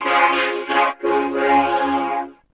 H363VOCAL.wav